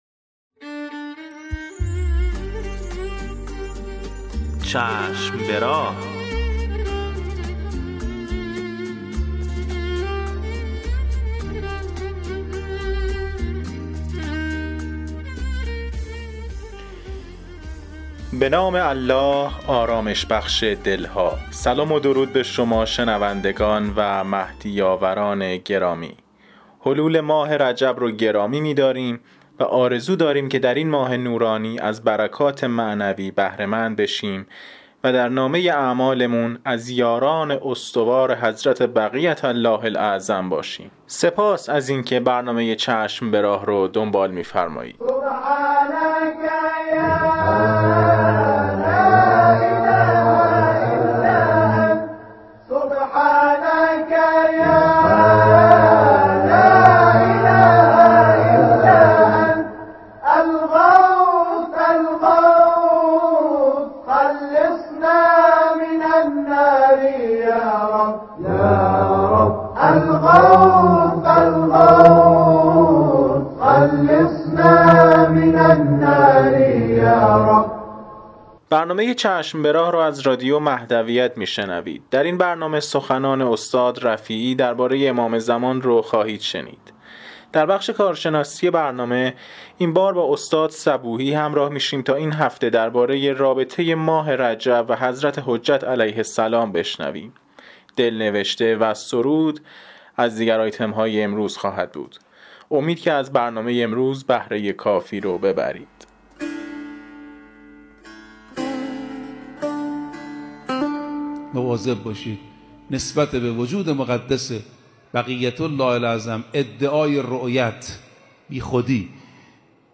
قسمت صد و شصت و یکم مجله رادیویی چشم به راه که با همت روابط عمومی بنیاد فرهنگی حضرت مهدی موعود(عج) تهیه و تولید شده است، منتشر شد.